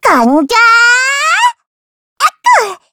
Taily-Vox_Skill1_kr.wav